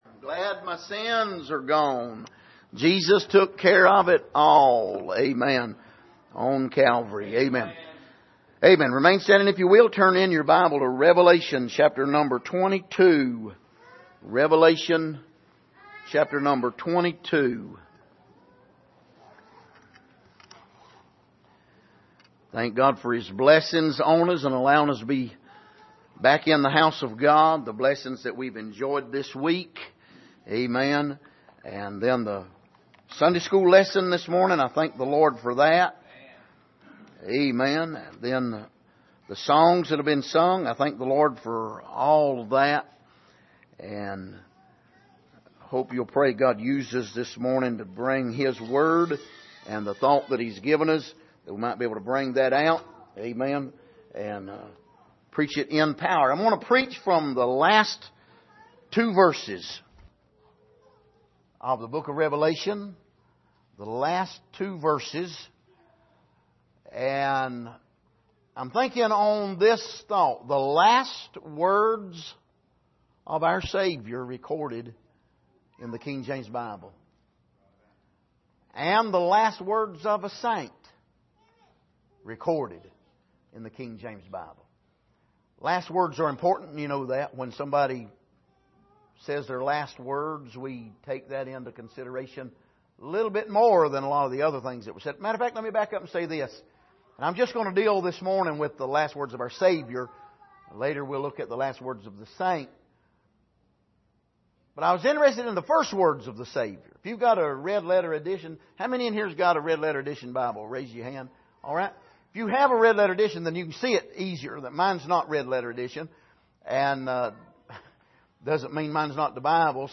Passage: Revelation 22:20-21 Service: Sunday Morning